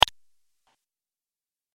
جلوه های صوتی
دانلود صدای کلیک 18 از ساعد نیوز با لینک مستقیم و کیفیت بالا
برچسب: دانلود آهنگ های افکت صوتی اشیاء دانلود آلبوم صدای کلیک از افکت صوتی اشیاء